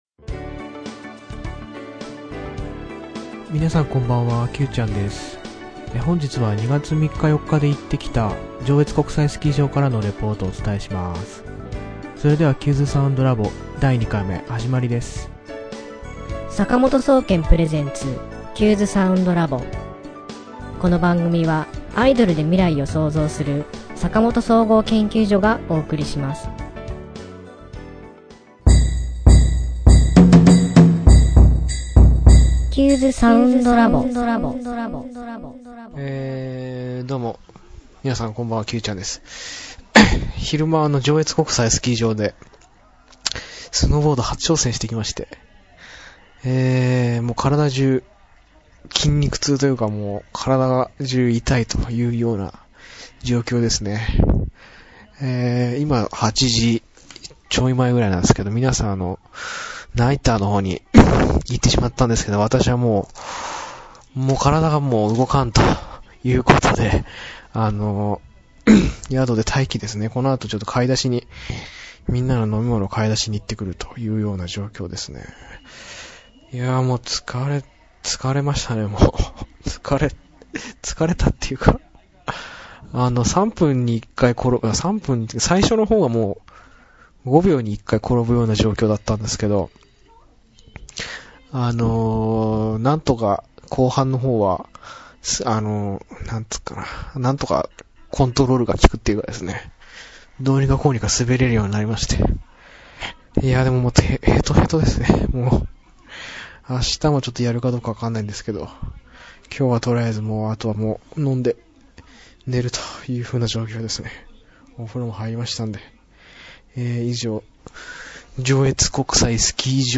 ポッドキャスティング、第2回は旅行先の宿とスキー場のリフトの上からレポートをお伝えします。